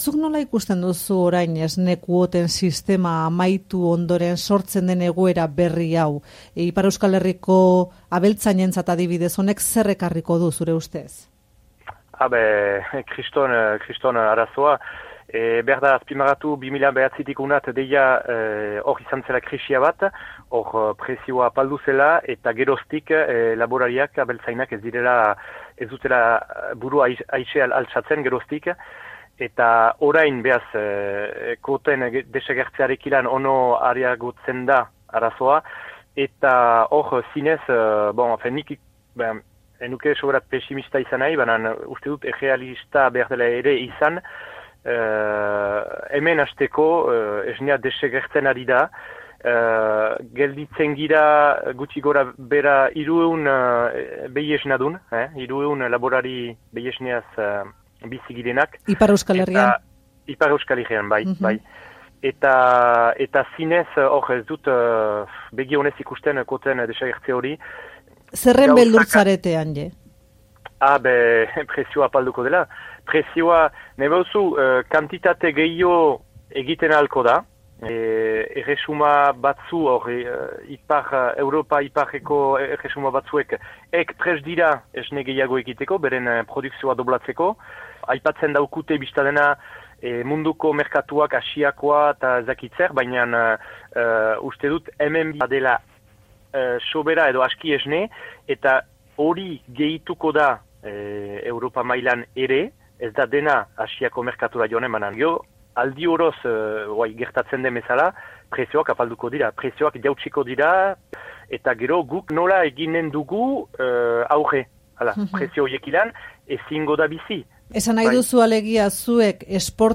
Euskadi irratitik hartua, 2015-04-15.